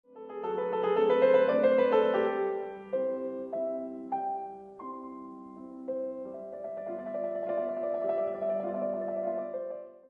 Rondos, Fantasies, Variations
Piano